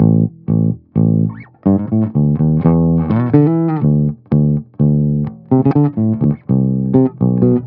03 Bass Loop A.wav